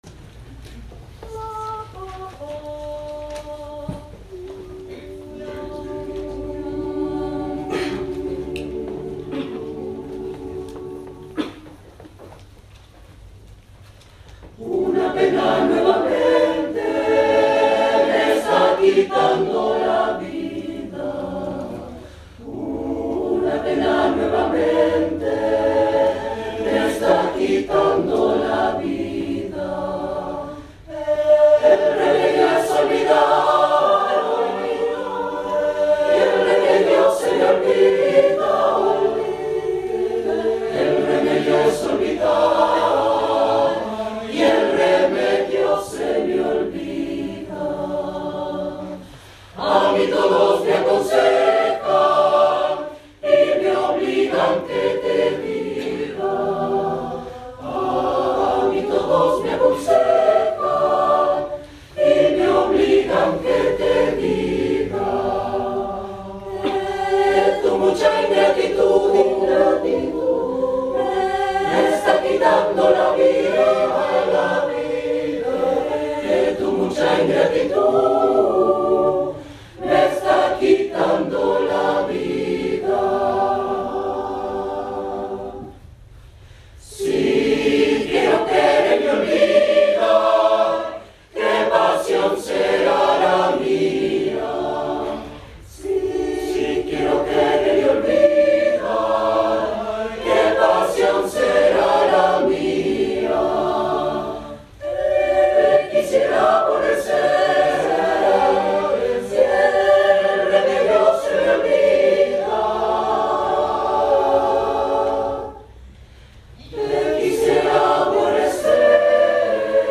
Negro Spiritual